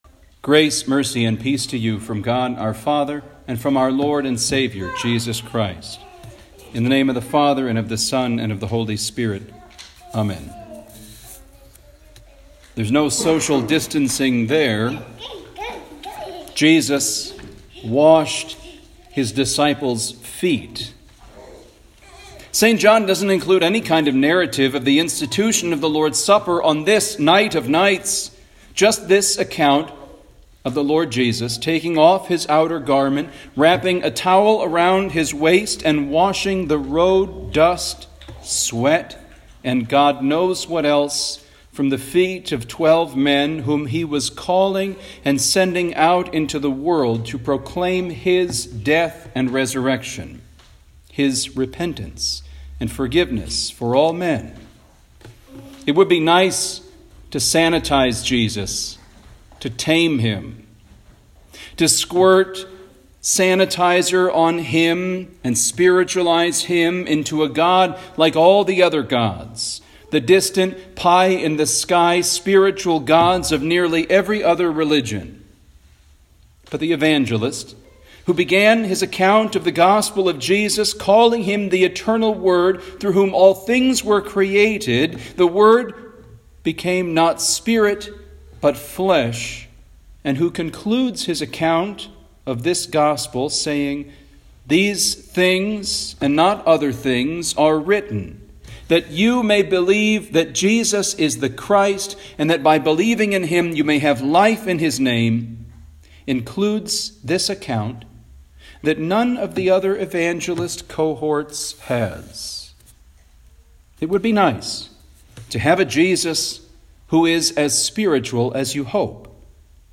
Home › Sermons › Maundy Thursday